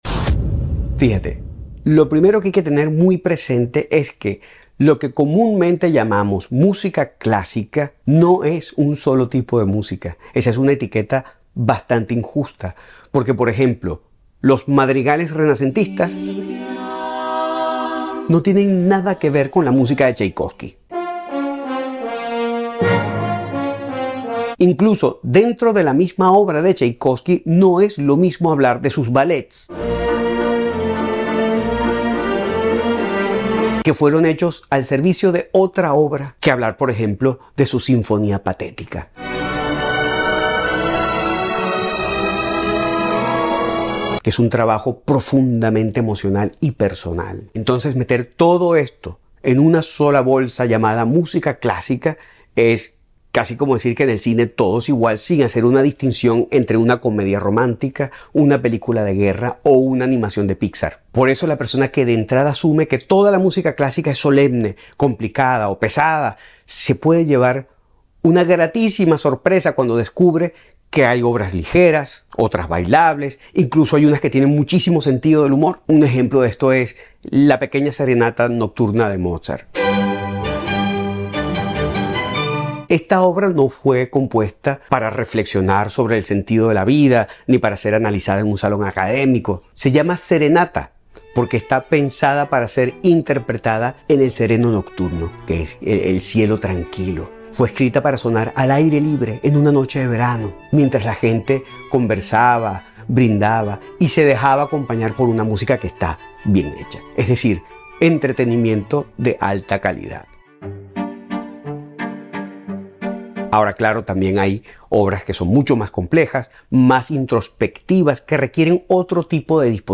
MUSICA CLASICA Sound Effects Free Download